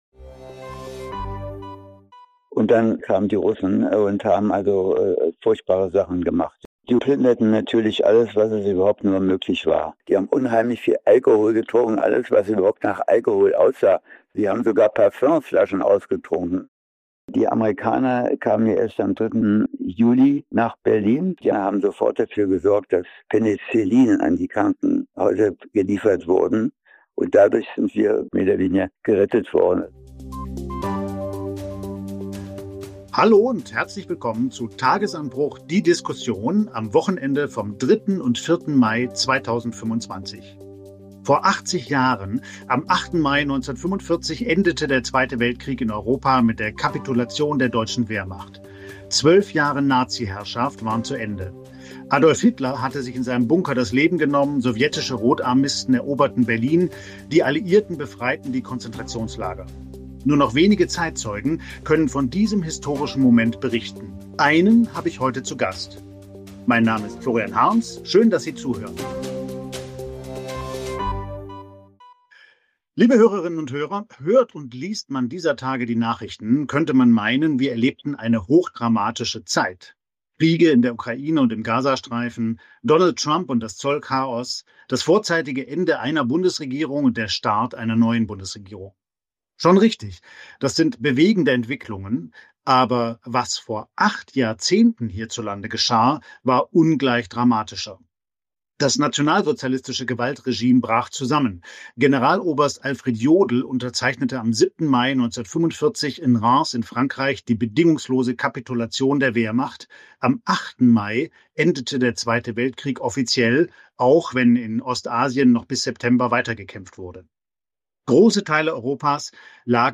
Deutschland, Mai 1945: Ein Zeitzeuge berichtet, wie es war